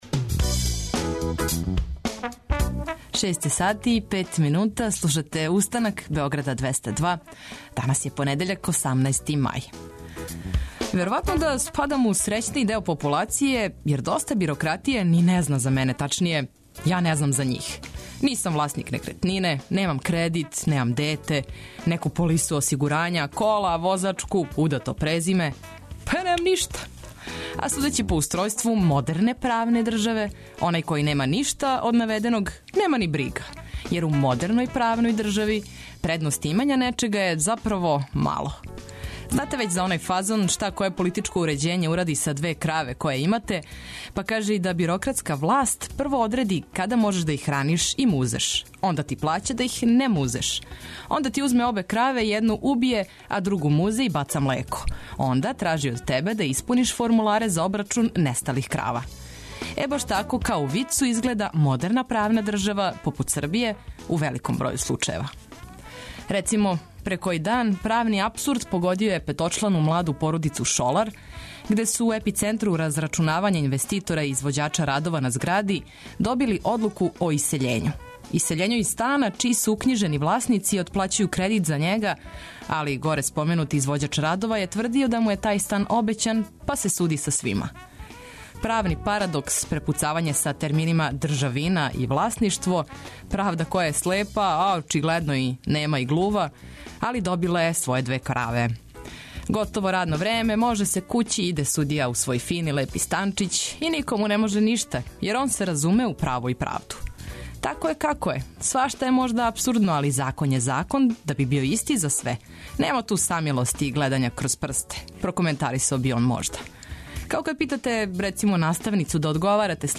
Мозак разбуђујемо корисним и интересантним информацијама, а ногу испод јоргана разбуђујемо добром музиком!